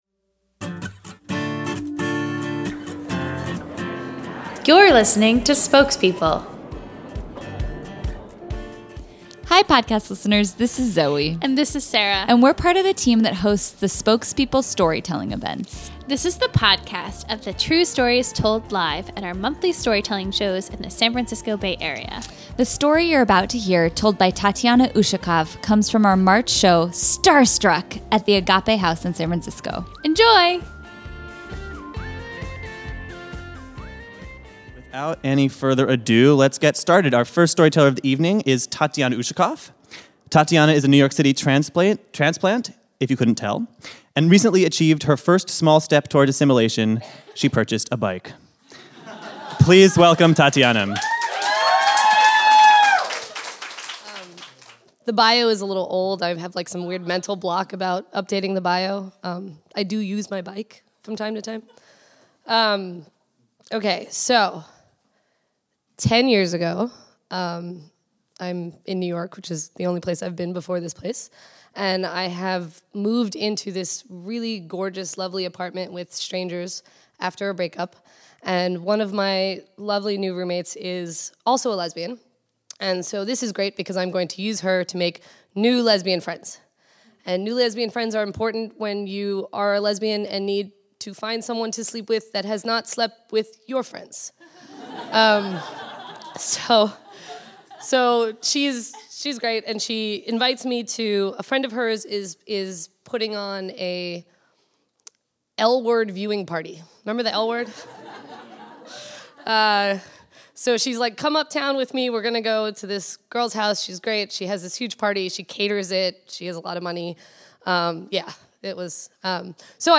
An evening watching the L Word turns into a night of celebrity courtship in the former hottest corner of Manhattan. This story was told live at our March 2014 show, "Starstruck."